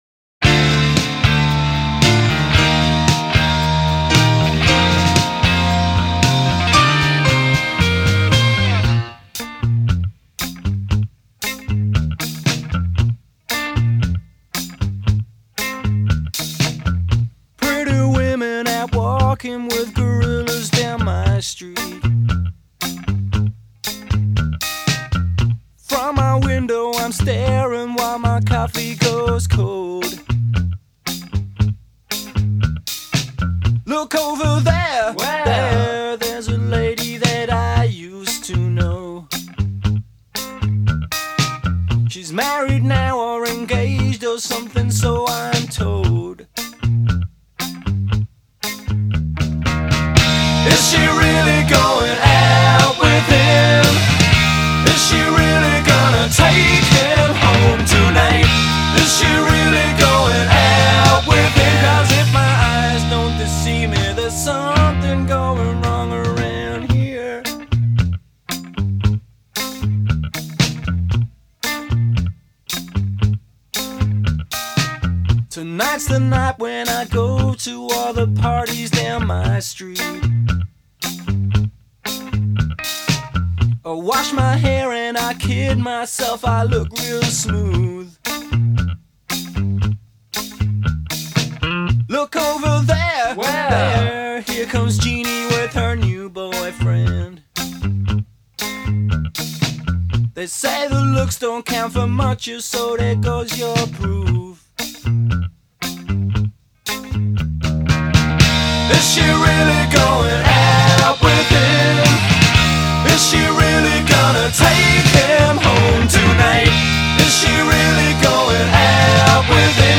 New Wave
surrounded by a catchy subdued melodic backdrop.